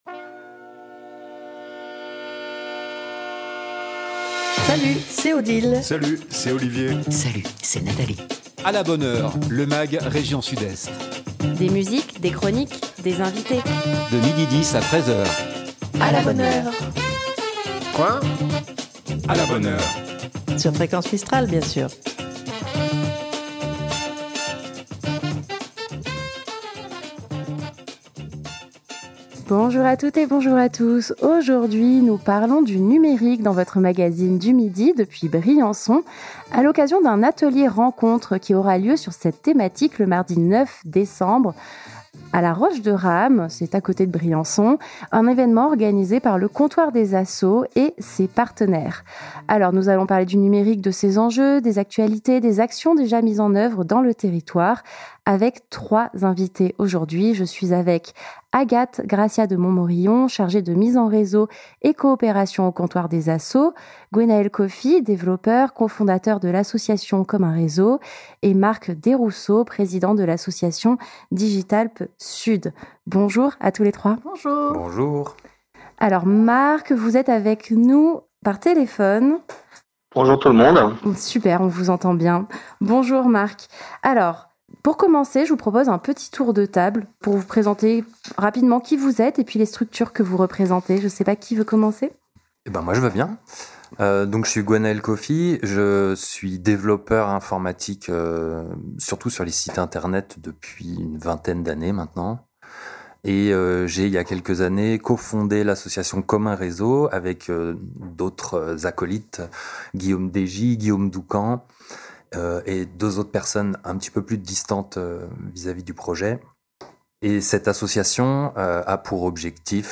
" Bienvenue dans le magazine région Sud-Est de Fréquence Mistral !
De Marseille à Briançon en passant par Manosque, sans oublier Dignes les bains, et Gap, un magazine régional, un Mag rien que pour vous, des invité.e.s en direct, des chroniques musique, cinéma, humour, littéraire, sorties et sur divers thèmes qui font l’actualité. Aujourd'hui nous parlons du numérique à l'occasion d'un atelier-rencontre qui aura lieu sur cette thématique le mardi 9 décembre 2025, à La Roche-de-Rame avec et pour les acteurs du domaine.